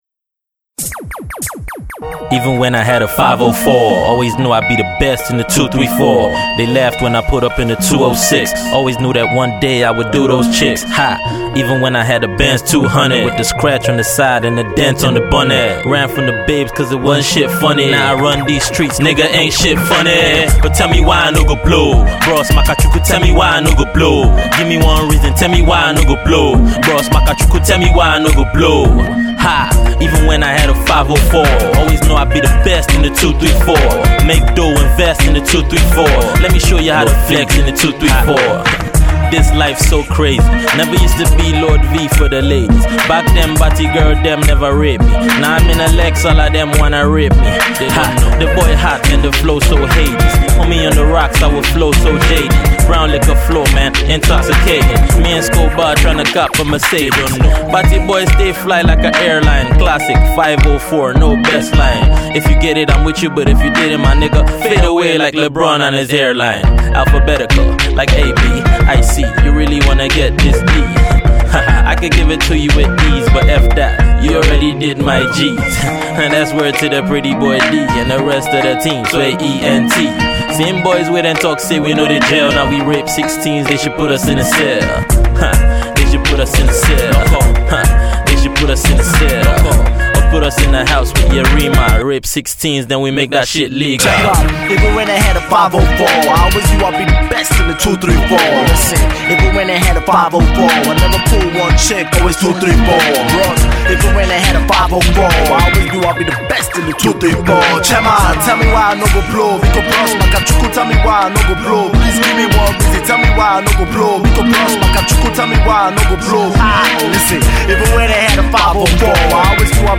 Its a Rap song